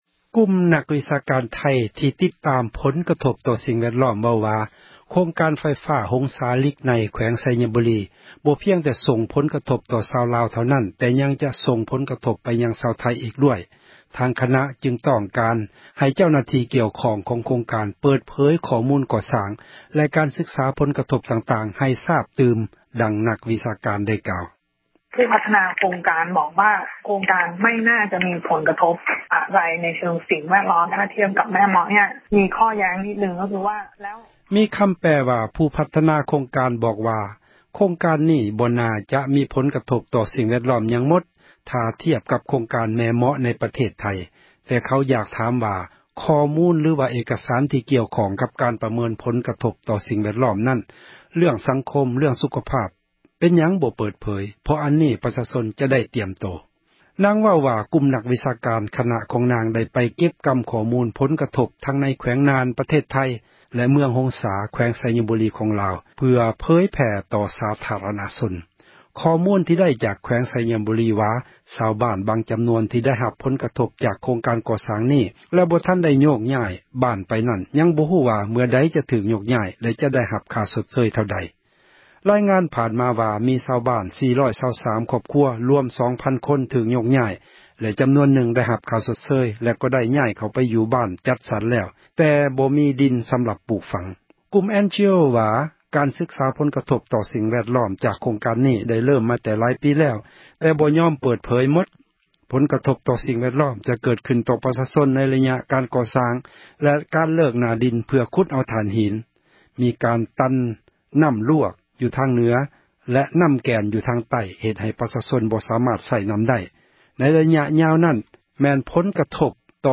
ນັກວິຊາການ ເວົ້າວ່າ: